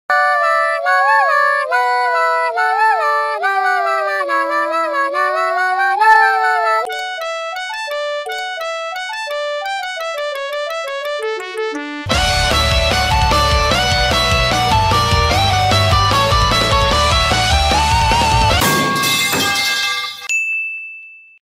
⏰ Which morning alarm do sound effects free download